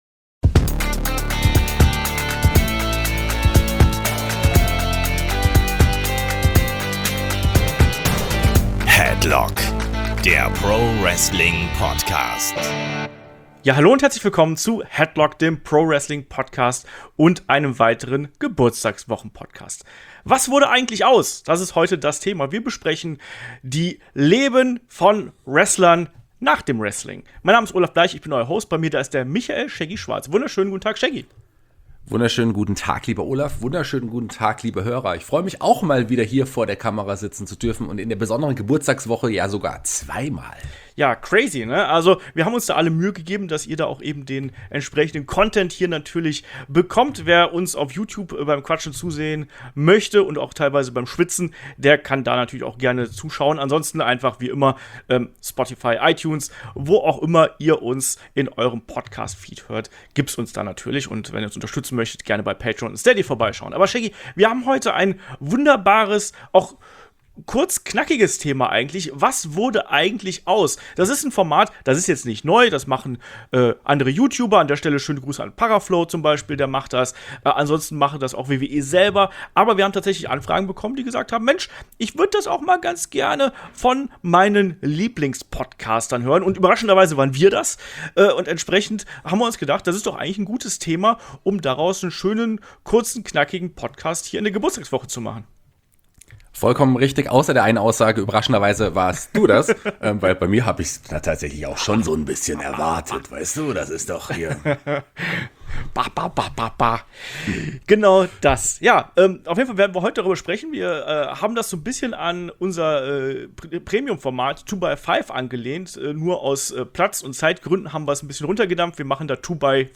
Kandidaten für ein Special gibt es natürlich viele, aber die beiden Podcaster haben sich jeweils drei ausgesucht und berichten kurz darüber, wie diese nach ihrer Wrestling-Karriere leben. Mit dabei u.a. Nathan Jones, Marc Mero, Dawn Marie und Kevin Thorn bzw. Mordecai.